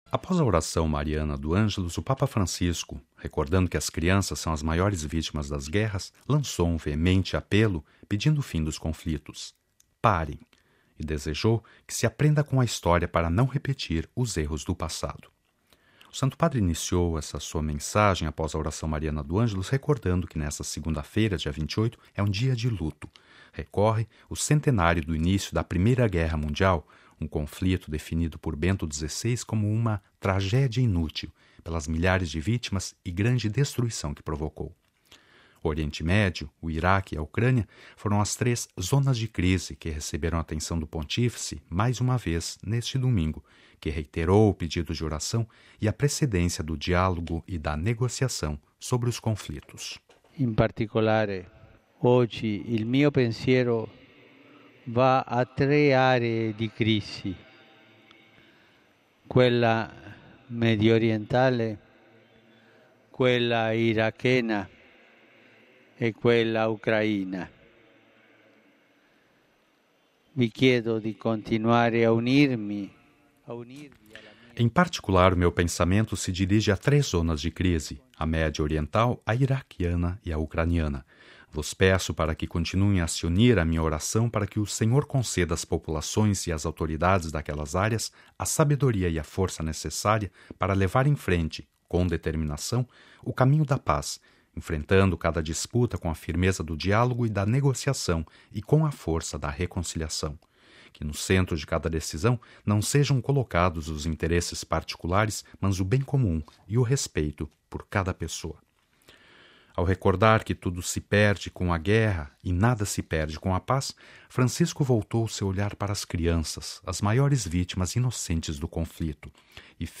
MP3 Cidade do Vaticano (RV) – Após a oração mariana do Angelus, o Papa Francisco – recordando que as crianças são as maiores vítimas das guerras - lançou um veemente apelo pelo fim dos conflitos: Parem!